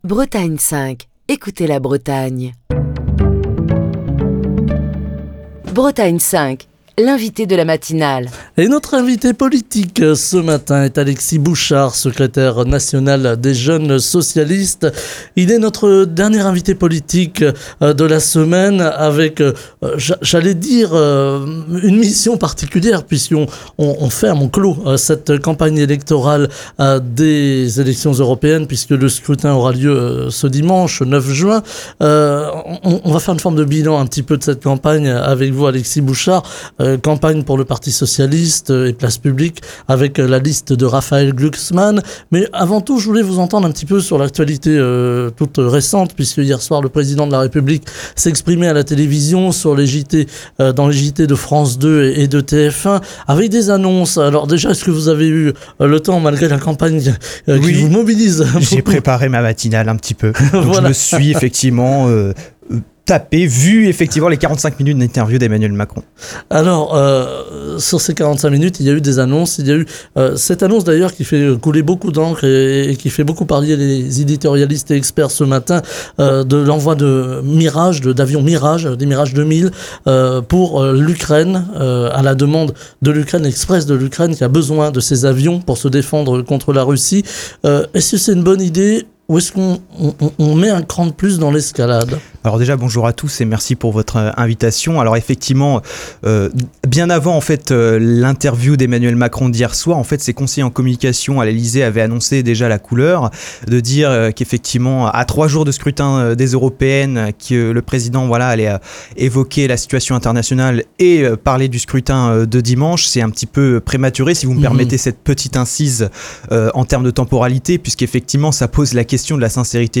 Écouter Télécharger Partager le podcast Facebook Twitter Linkedin Mail L'invité de Bretagne 5 Matin